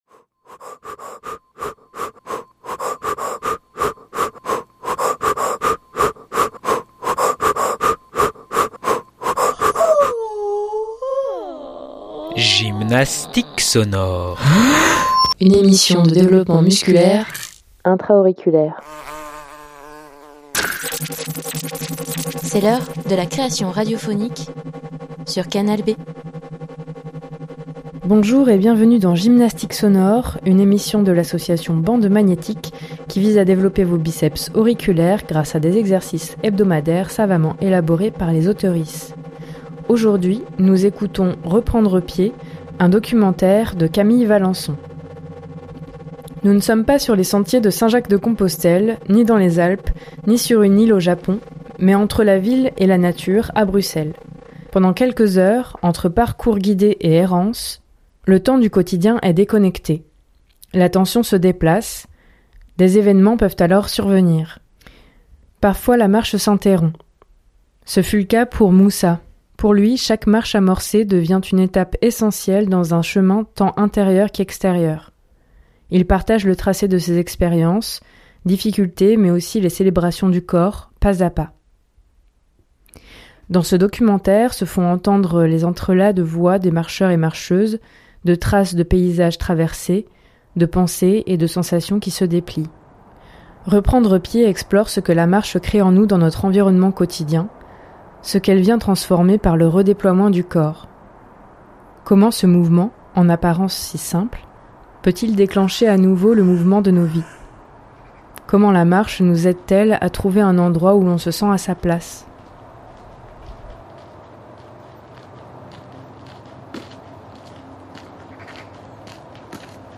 Nous ne sommes pas sur les sentiers de Saint-Jacques-de-Compostelle, ni dans les Alpes, ni sur une île au Japon, mais entre la ville et la nature à Bruxelles.
Dans ce documentaire se font entendre un entrelacs de voix de marcheur·euses, de traces de paysages traversés, de pensées et de sensations qui se déplient. Reprendre pied explore ce que la marche crée en nous dans notre environnement quotidien, ce qu’elle vient transformer par le redéploiement du corps.